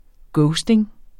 ghosting substantiv, fælleskøn Bøjning -en Udtale [ ˈgɔwsdeŋ ] Oprindelse kendt fra 2001 fra engelsk ghosting med samme betydning, af substantivet ghost 'spøgelse' Betydninger 1.